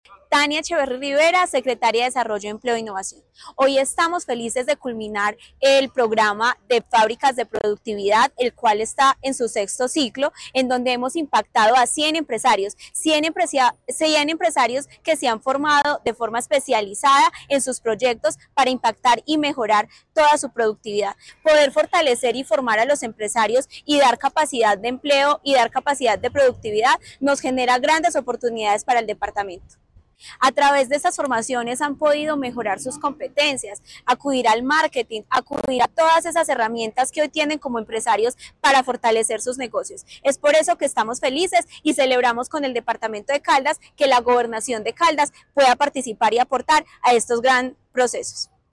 Tania Echeverry, secretaria de Desarrollo, Empleo e Innovación de Caldas.